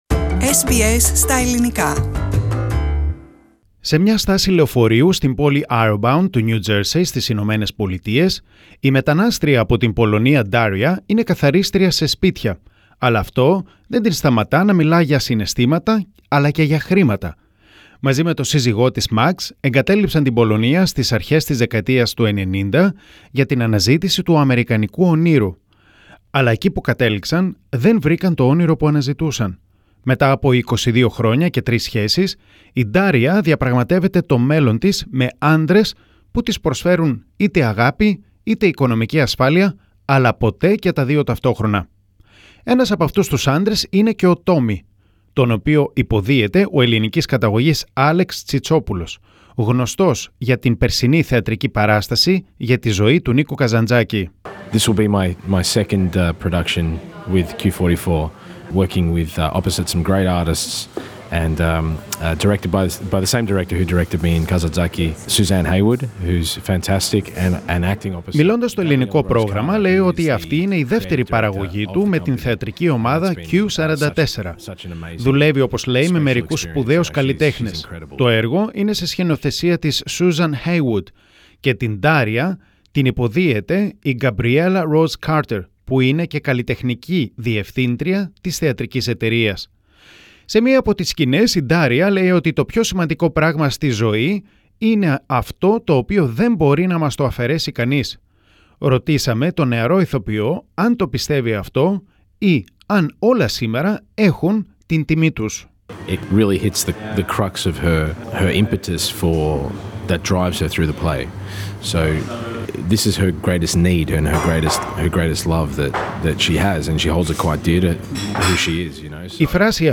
Η συνέντευξη